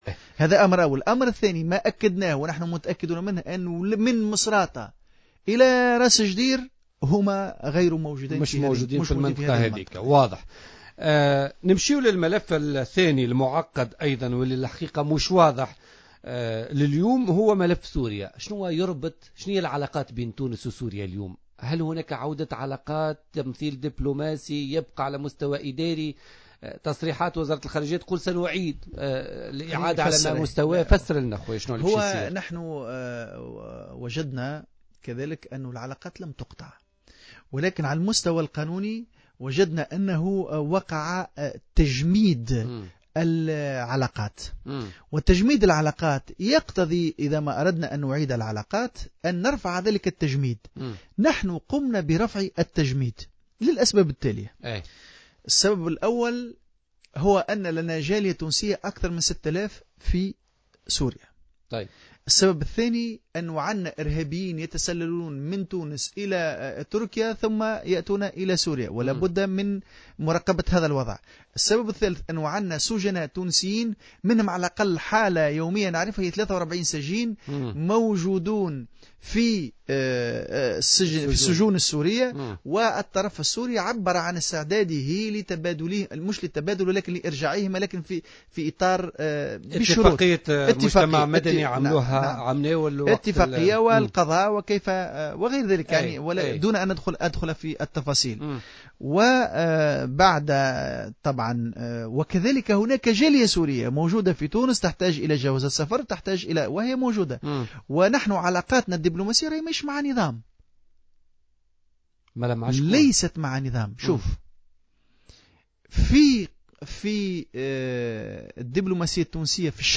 بين التوهامي العبدولي، كاتب الدولة لدى وزير الخارجية المكلف بالشؤون العربية والافريقية في تصريح للجوهرة أف أم اليوم الاثنين أن العلاقات مع سوريا لم تقطع وإنما وقع تجميدها، مؤكدا أن تونس قررت رفع التجميد لعدة أسباب.